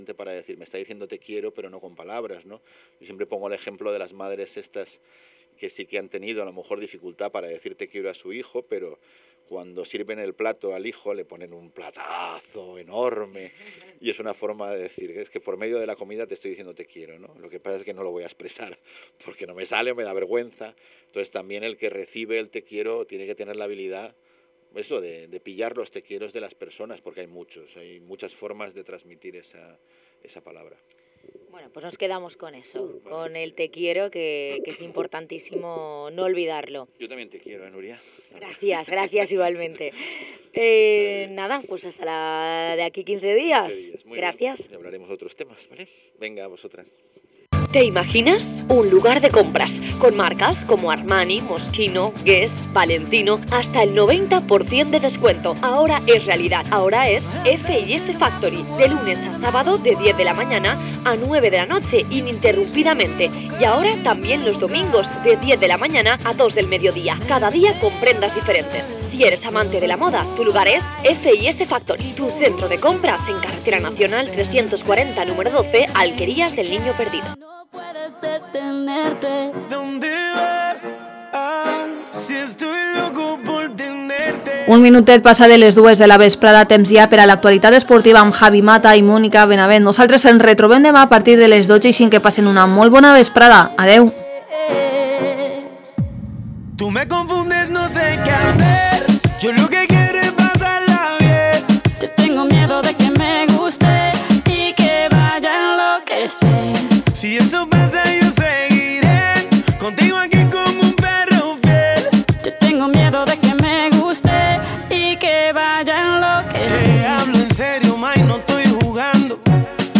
Hoy escuchamos al entrenador del Villarreal Javier Calleja en el primer programa de 2018.